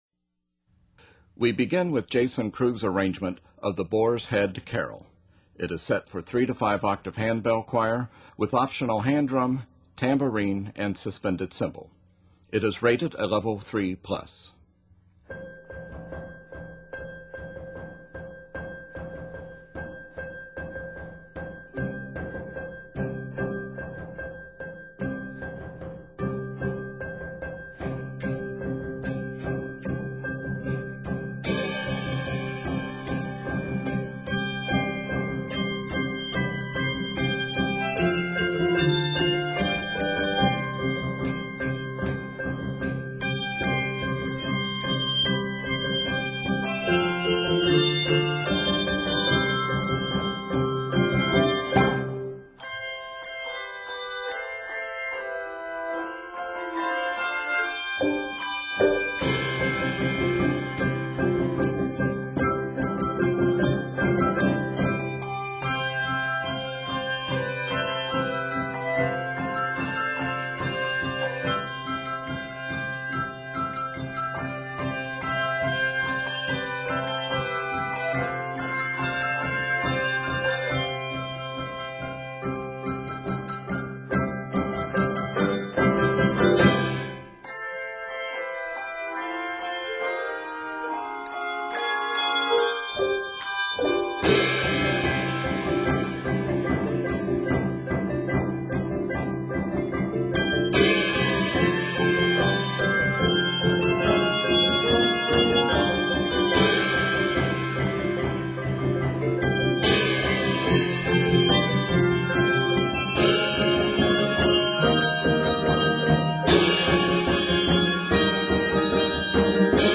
scored in C Major